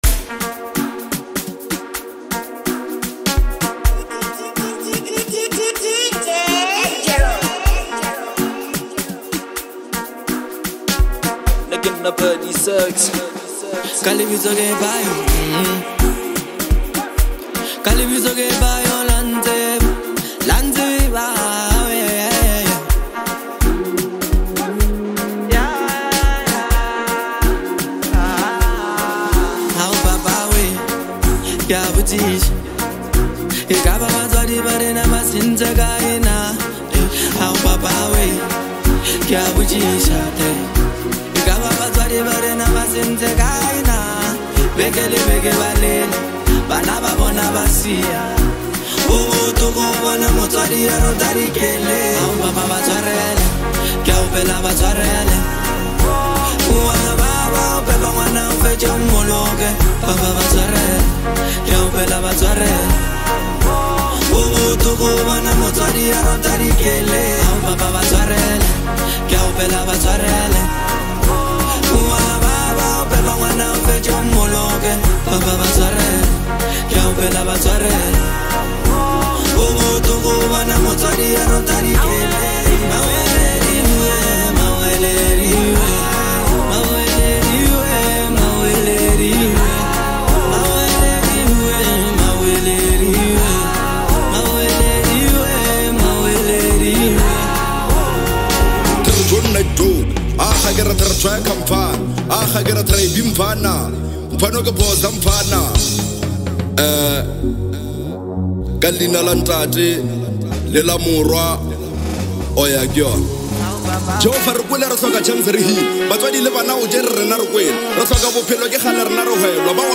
Lekompo